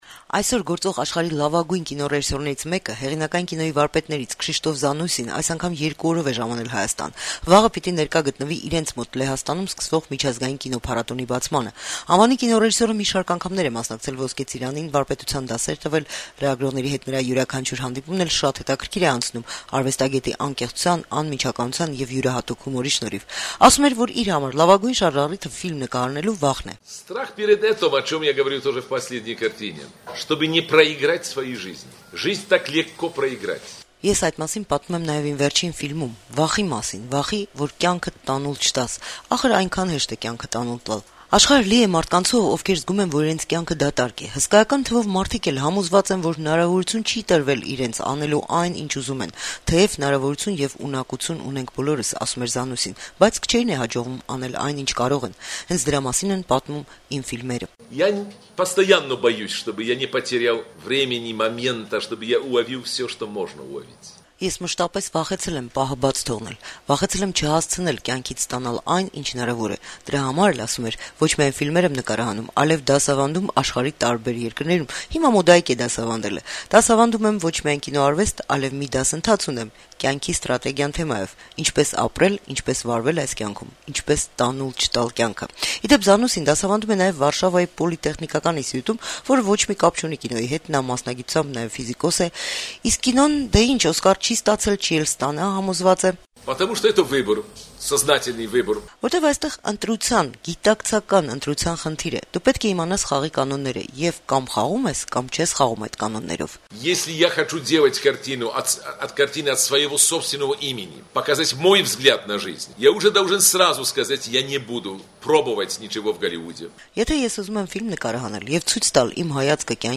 «Ոսկե ծիրան» միջազգային կինոփառատոնի պատվավոր հյուրերից հեղինակային կինոյի համաշխարհային ճանաչում վայելող լեհ կինոռեժիսոր Քշիշտոֆ Զանուսին հինգշաբթի օրը լրագրողների հետ հանդիպմանը ասաց, որ ֆիլմ նկարահանելու լավագույն շարժառիթը իր համար վախն է: